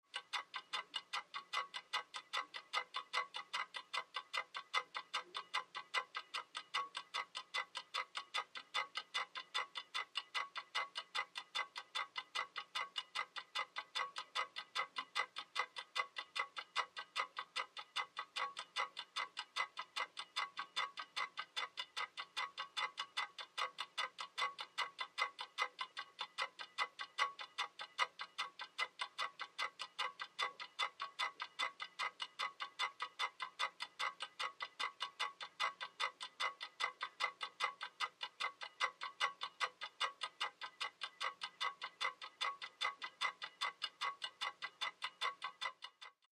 Mantle Clock | Sneak On The Lot
CLOCKS MANTLE CLOCK: INT: Large mantle clock strikes 8 0`clock, church bell like.